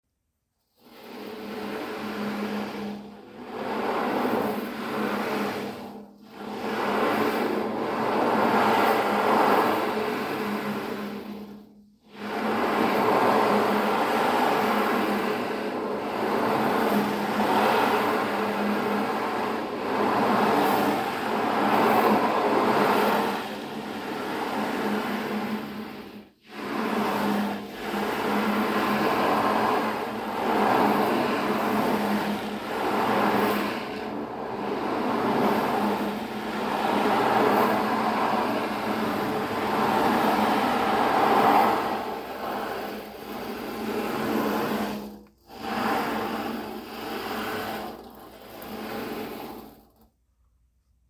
Ocean Drum
• Sustainably sourced goat skin
• Sounds likes the sea washing up on the shore
• The bigger the drum, the deeper the sound
• Sound: Produces realistic ocean wave sounds, with deeper tones in larger sizes
16-ocean-drum.mp3